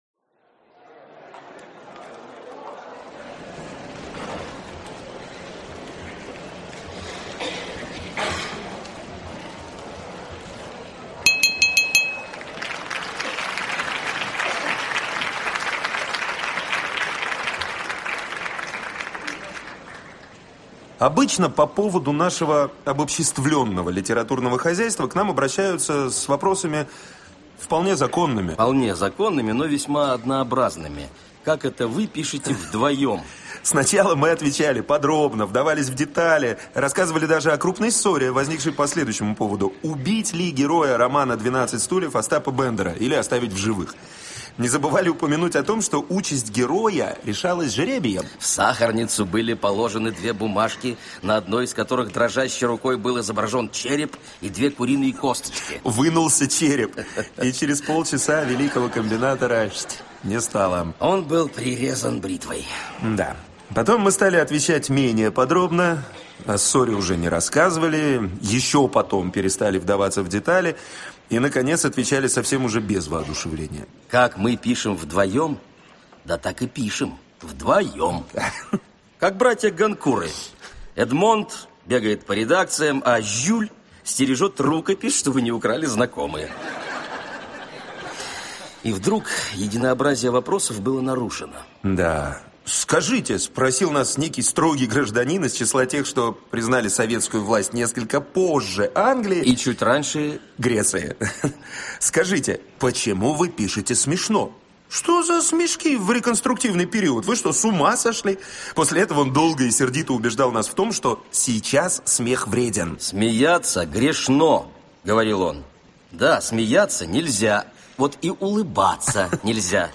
Аудиокнига Золотой теленок | Библиотека аудиокниг